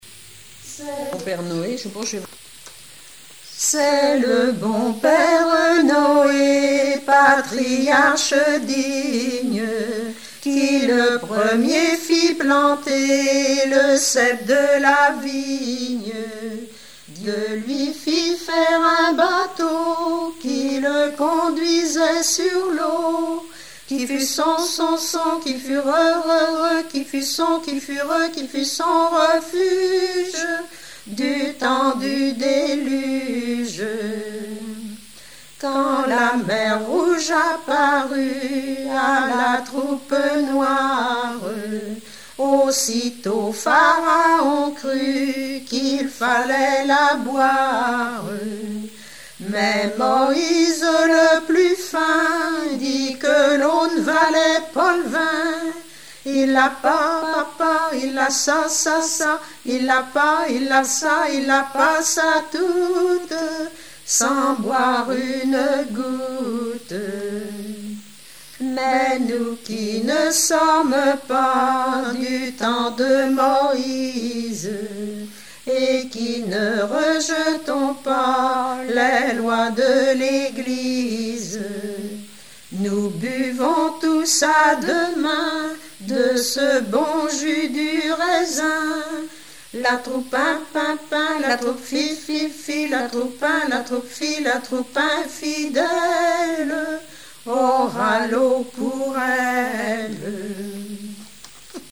Répertoire de chansons populaires et traditionnelles
Pièce musicale inédite